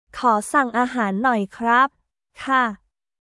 コー サン アーハーン ノイ カップ／カー